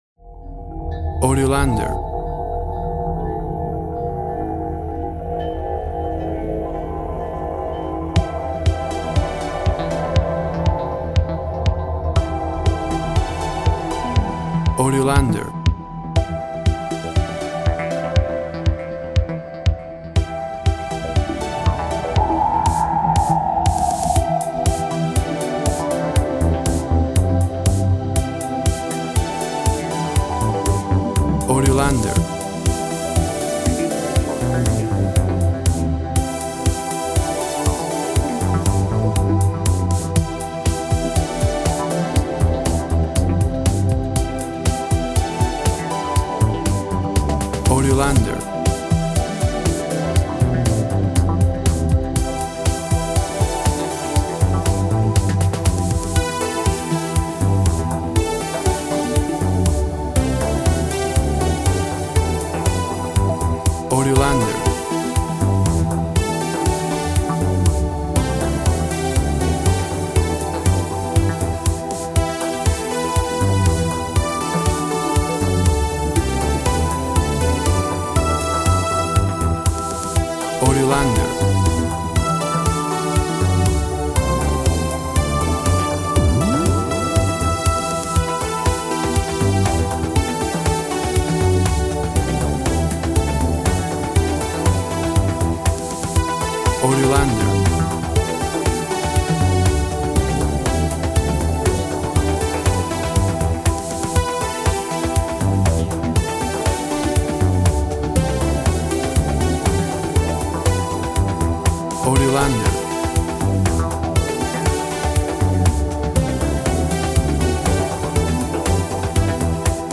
Tempo (BPM) 120